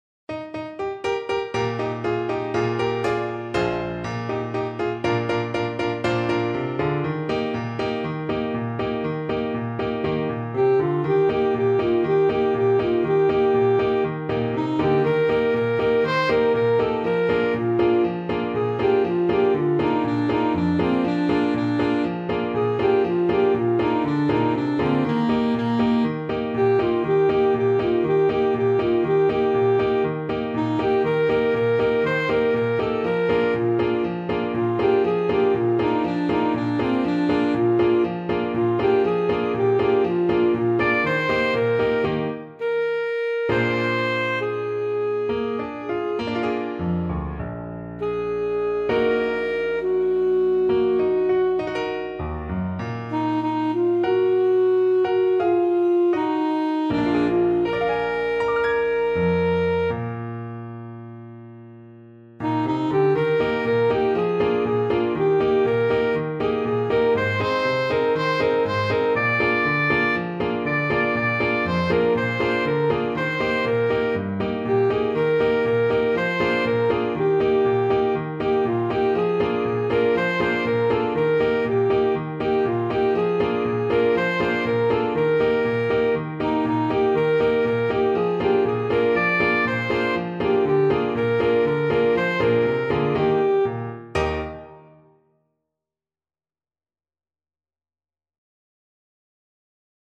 Alto Saxophone
2/4 (View more 2/4 Music)
Mariachi style =c.120
Mexican